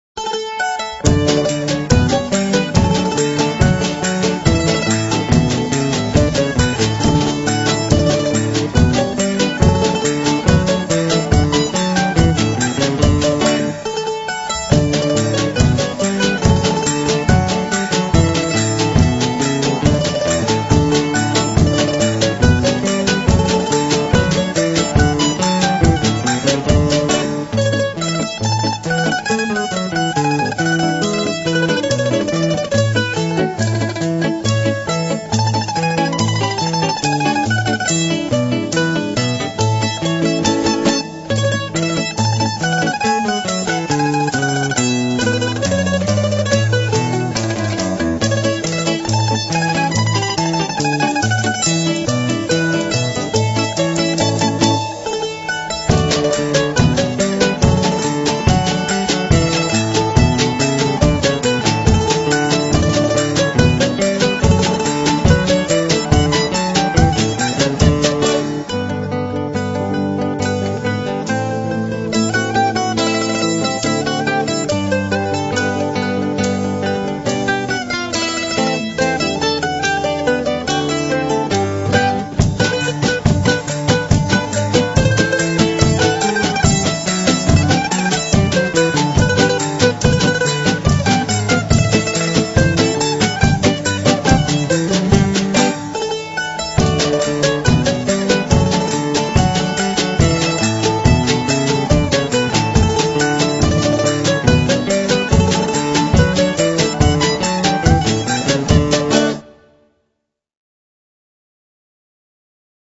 Amorosetta – Polka (AUDIO durata 1:52) 1.